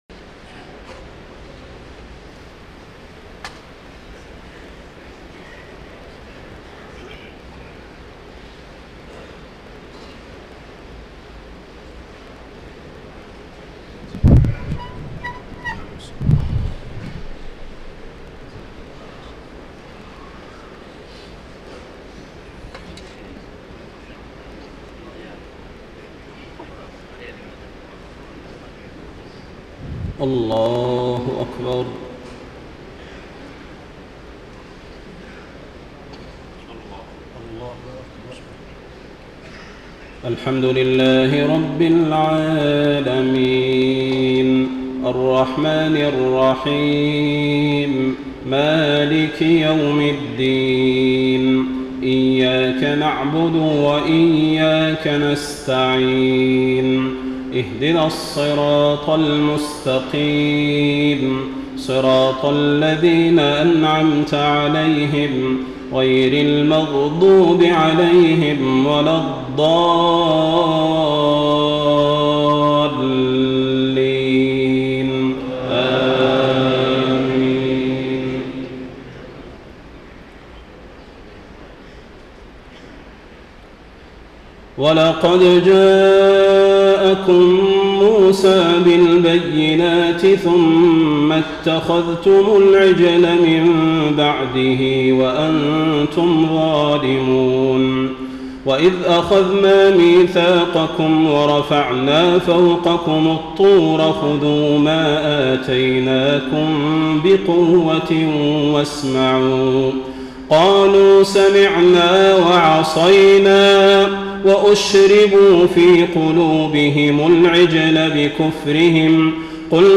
تهجد ليلة 21 رمضان 1433هـ من سورة البقرة (92-141) Tahajjud 21 st night Ramadan 1433H from Surah Al-Baqara > تراويح الحرم النبوي عام 1433 🕌 > التراويح - تلاوات الحرمين